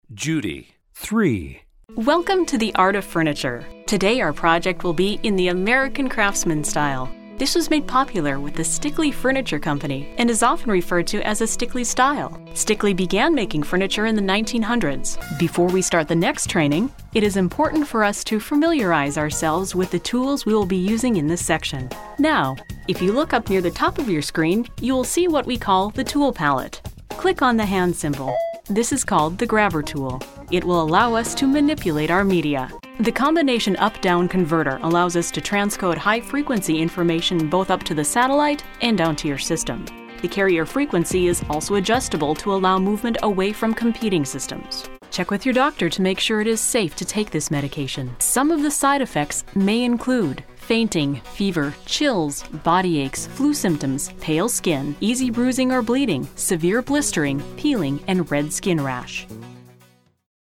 Friendly Professional Voice Over Talent | VoicesNow Voiceover Actors
Male and Female Friendly voice over talent. Free voice over demos.